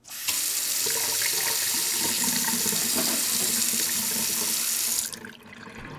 Kitchen Sink Water Running Sound
household
Kitchen Sink Water Running